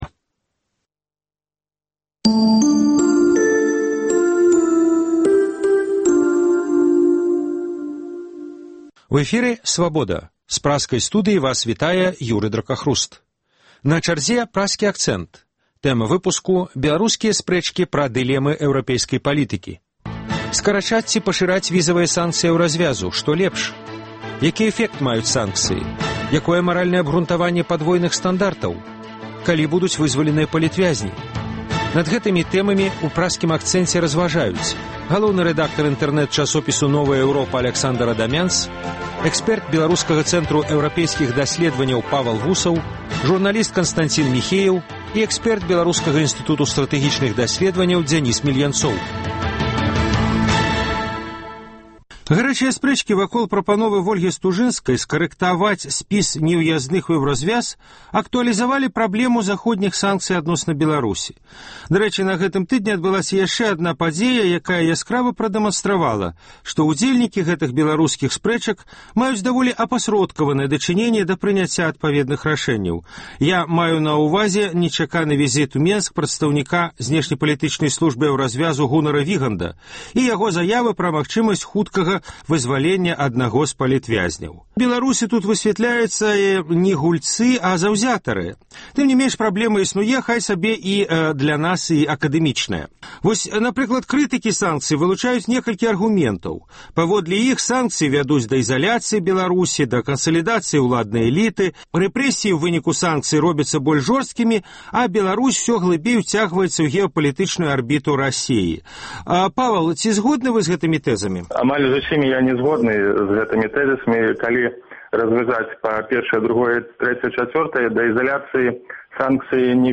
Калі будуць вызваленыя палітвязьні? Па гэтых тэмах у “Праскім акцэнце” вядуць спрэчку: